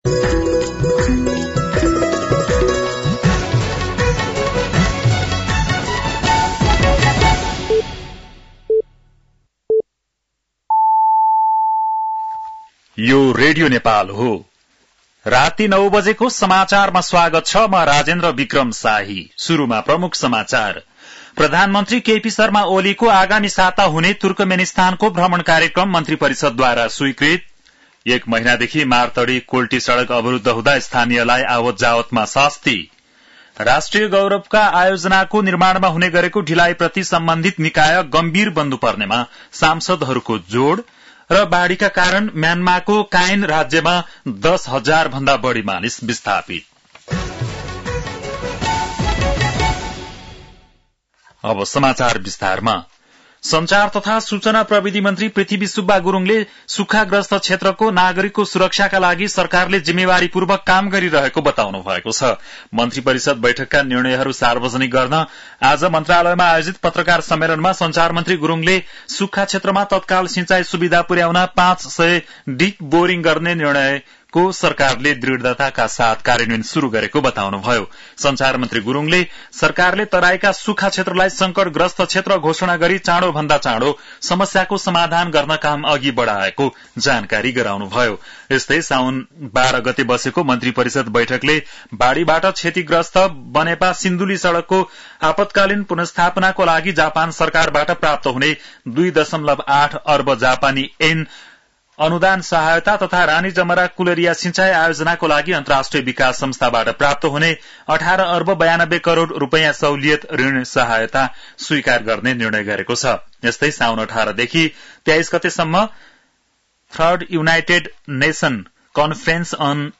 बेलुकी ९ बजेको नेपाली समाचार : १५ साउन , २०८२